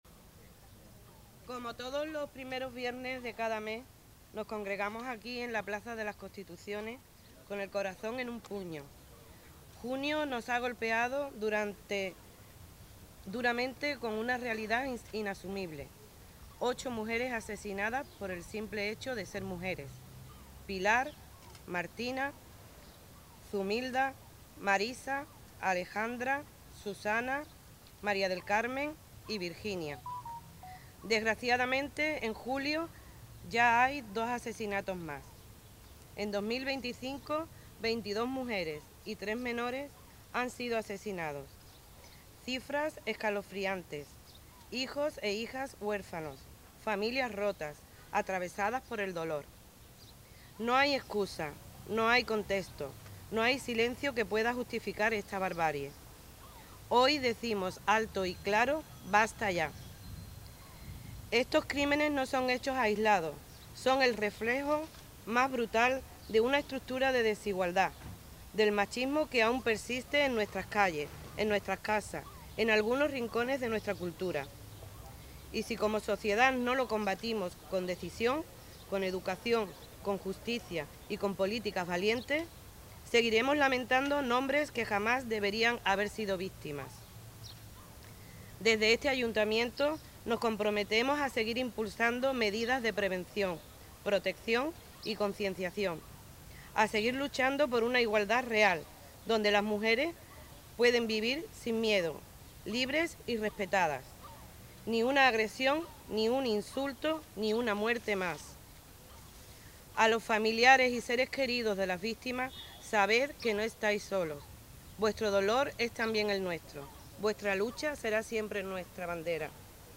MINUTO DE SILENCIO Y MANIFIESTO  TOTAL  MARIA COLLADO 4 DE JULIO DE 2025.mp3